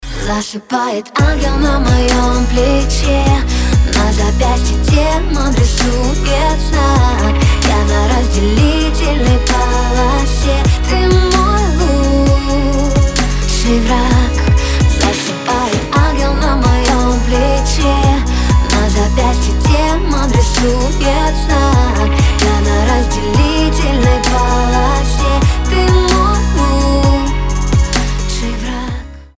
• Качество: 320, Stereo
поп
женский вокал
нежные